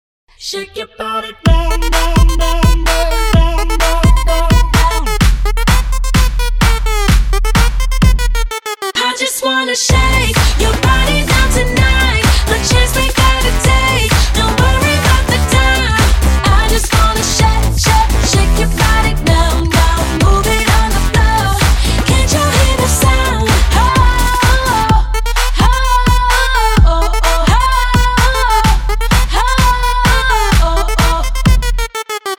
• Качество: 192, Stereo
заводные